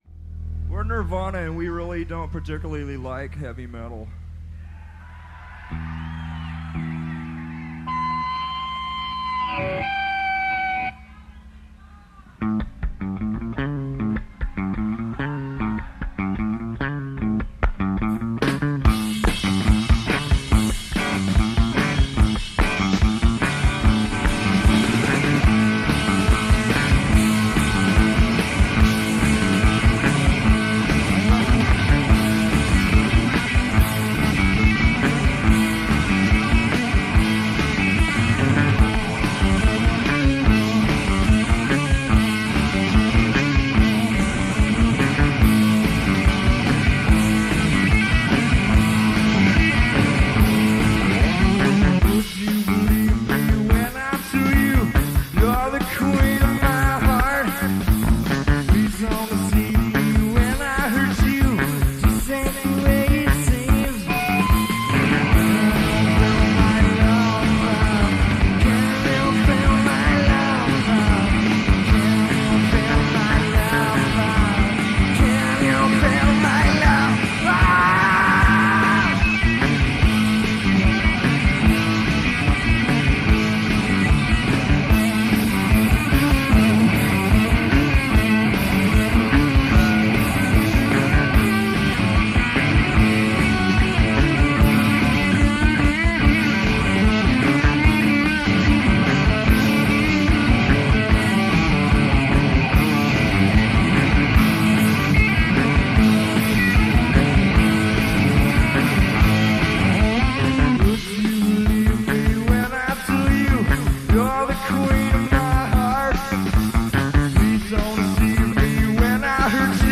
in concert
spiffed up, cleaned up and turbo charged